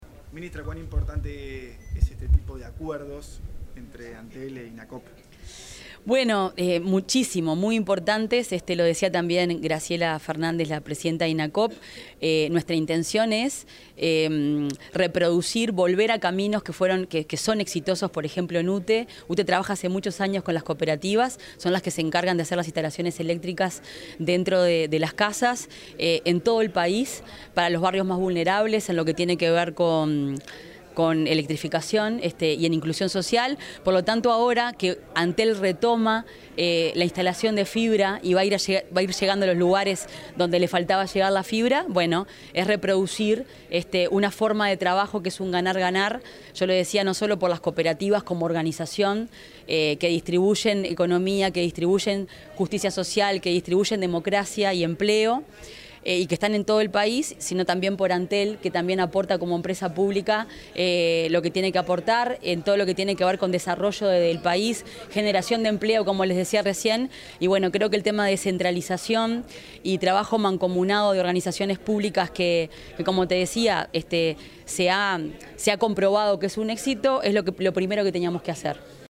Declaraciones de la ministra de Industria, Energía y Minería, Fernanda Cardona
Declaraciones de la ministra de Industria, Energía y Minería, Fernanda Cardona 03/09/2025 Compartir Facebook X Copiar enlace WhatsApp LinkedIn La ministra de Industria, Energía y Minería, Fernanda Cardona, efectuó declaraciones a la prensa durante la firma de un convenio entre Antel y el Instituto Nacional del Cooperativismo.